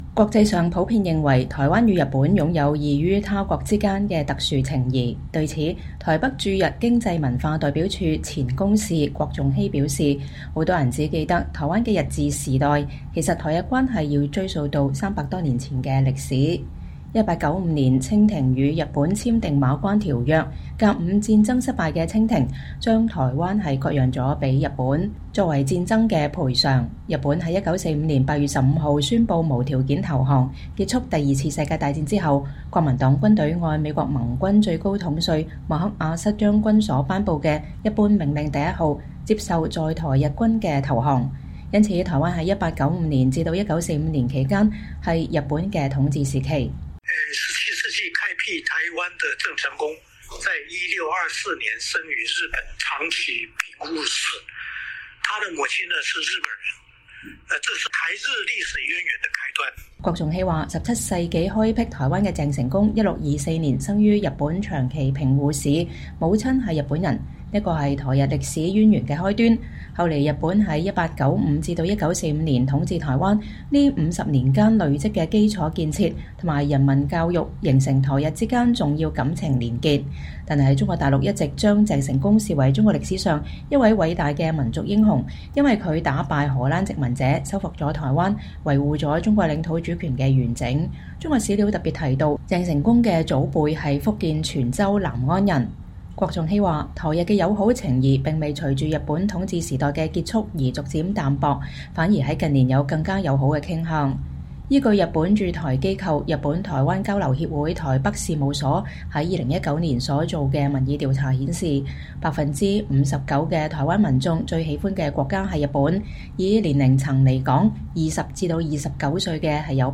專訪前台灣駐日副代表：台日情誼一衣帶水 無法取代
近來日本官方有別於往年，頻頻在台海議題上表態，在包括東京奧運等國際舞台上凸顯台灣的重要性。前台灣駐日公使郭仲熙(Chung-Hsi Kuo)近日在接受美國之音的專訪時分析了台日關係的複雜性、日本對台與對中外交的變化，以及美國始終無法取代台日情誼的原因。